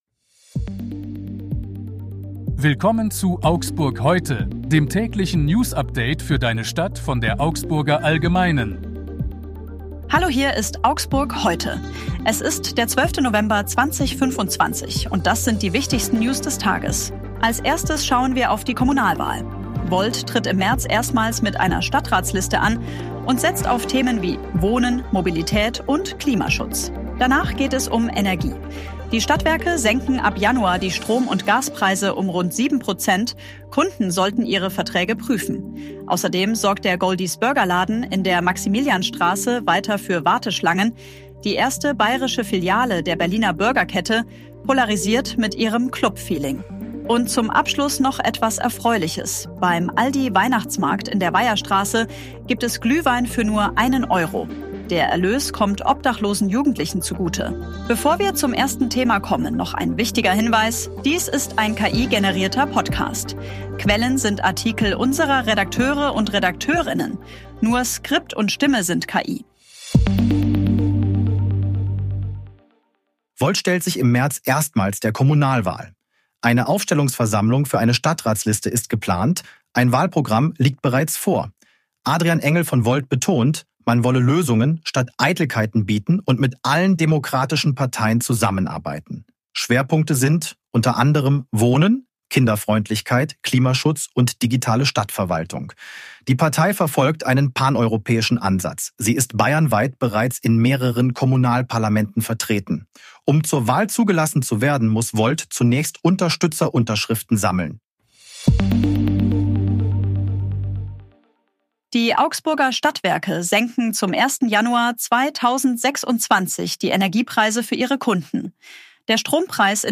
Redakteurinnen. Nur Skript und Stimme sind KI